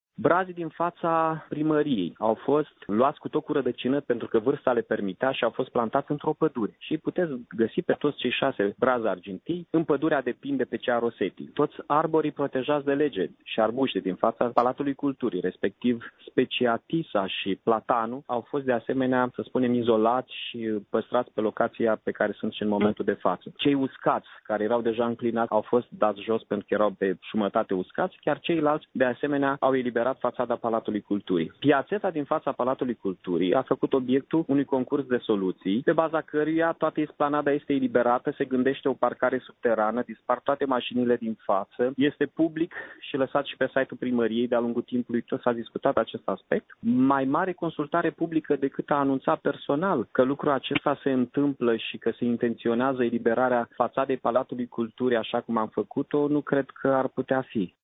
Primarul Mihai Chirica a adăugat că piaţeta din faţa Paltului va fi refăcută şi pentru acest lucru a existat un concurs de proiecte. În privinţa lipsei unei consultări publice, Mihai Chirica a spus că acţiunea a fost anunţată: